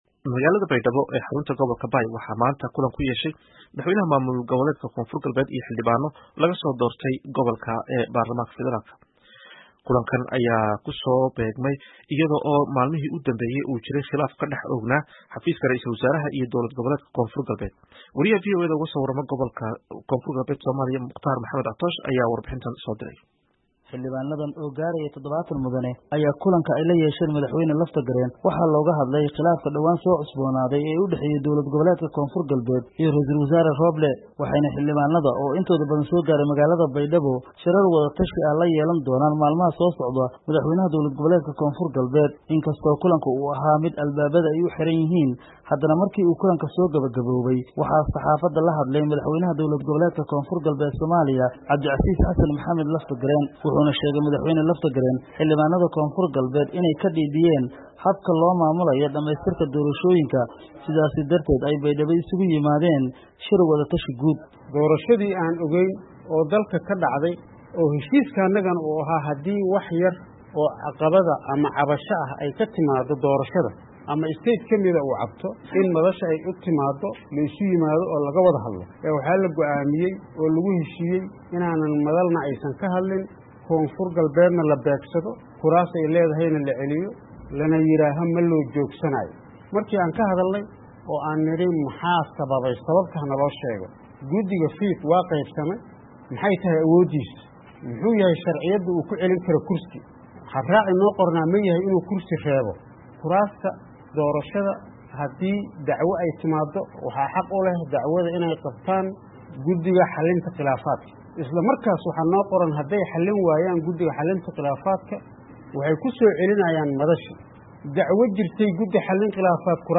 Madaxweynaha Koonfur Galbeed Cabdicasiis Laftagareen oo ka hadlay kulan ka dhacay Baydhaba ayaa dhaleeceeyey guddiga doorashada iyo ra’iisul wasaare Maxamed Xuseen Rooblee.